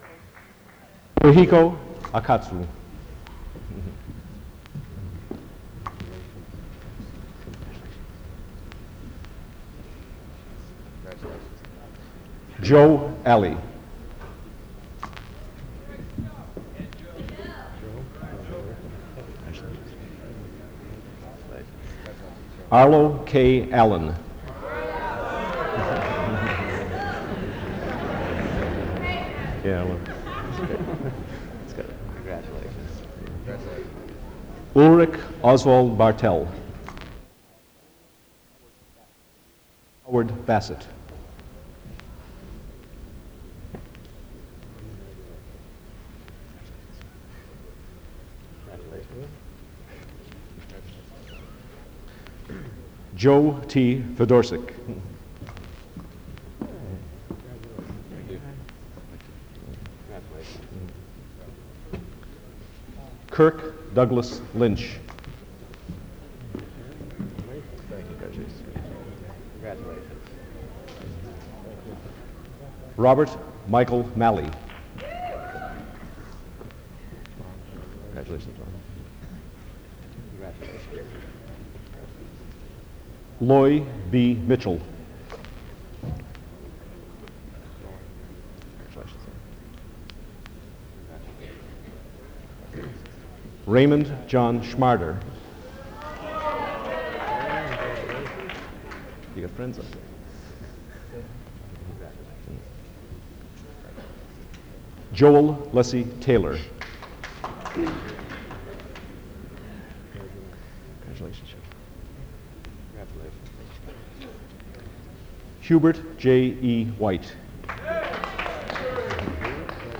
1982--Commencement--010 | Berklee Archives